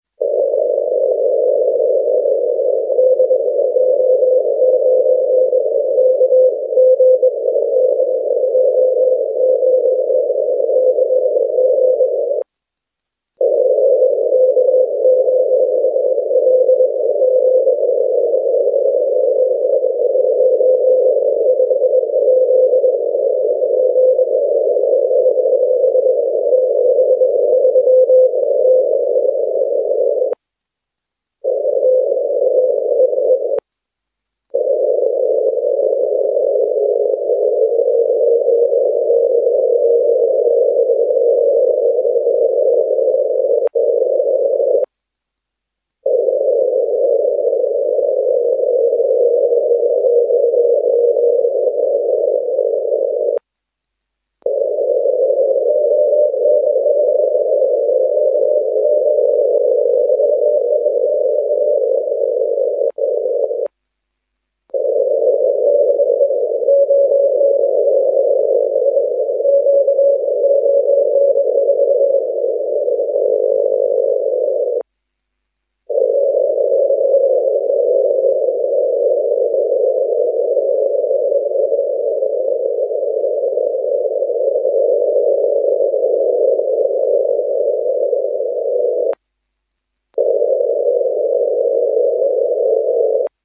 Variation of CW tone is caused by my RX-VFO manipulation for clarity.